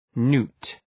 Προφορά
{nu:t}